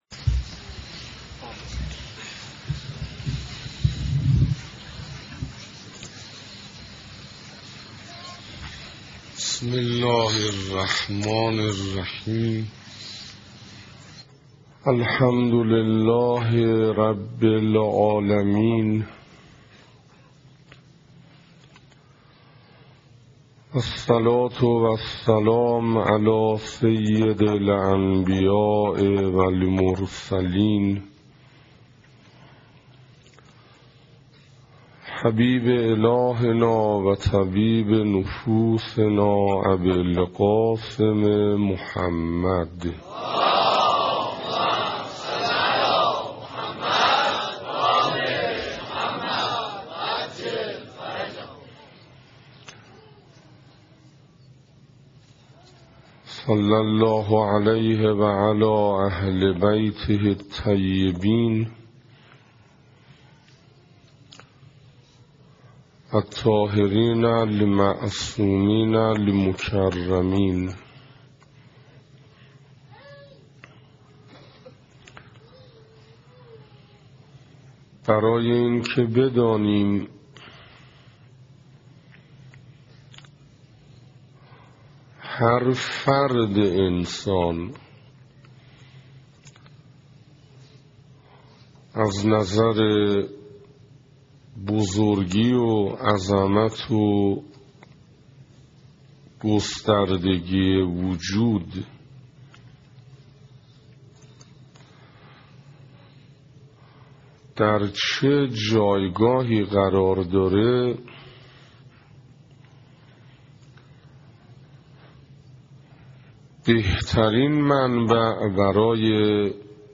سخنراني نهم
صفحه اصلی فهرست سخنرانی ها ارزيابي ارزش انسان (2) سخنراني نهم (تهران حسینیه صاحب‌الزمان(عج)) محرم1428 ه.ق - بهمن1385 ه.ش دانلود متاسفم..